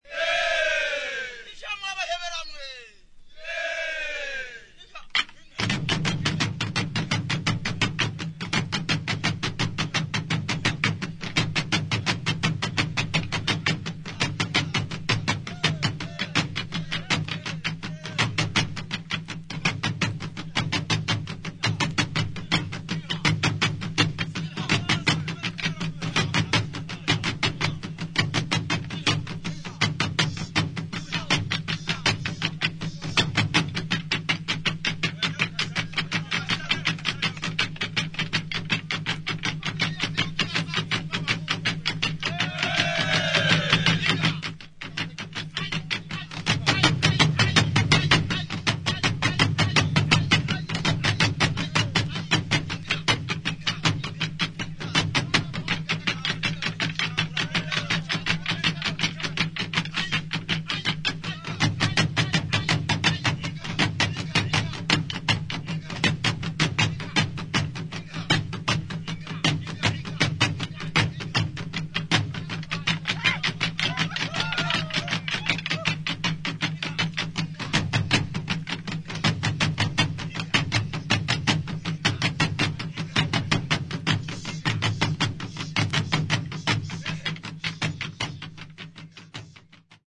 土着的なアフリカものからニューウェーブ、ダブ、ロック、現代音楽までジャンルをクロスオーバーした良作が多数収録